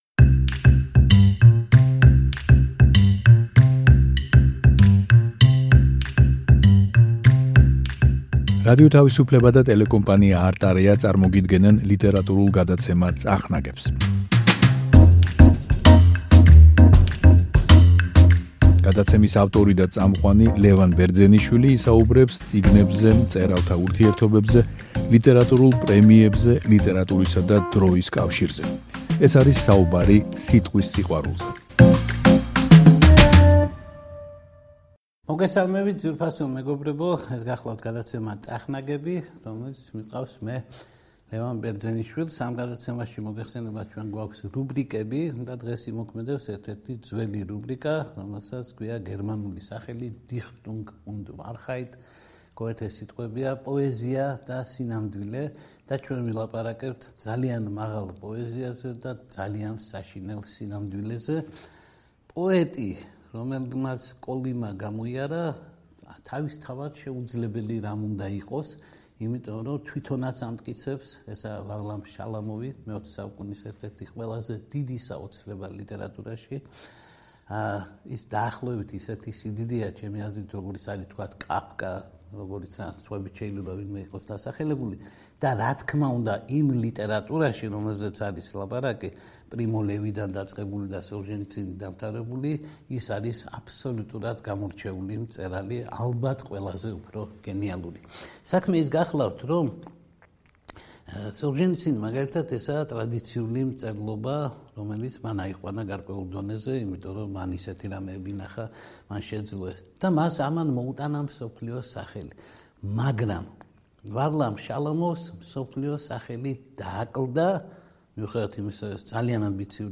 ლიტერატურული გადაცემა „წახნაგები“ რუბრიკით Dichtung und Wahrheit („პოეზია და სინამდვილე“) გთავაზობთ საუბარს ისტორიული სინამდვილის ასახვაზე ლიტერატურაში, კერძოდ, საბჭოთა გულაგის საშინელებათა ასახვაზე დიდი რუსი მწერლის, ვარლამ შალამოვის, მოთხრობებში, რომელთაც საერთო სათაური, „კოლიმური მთხრობები“, აერთიანებთ.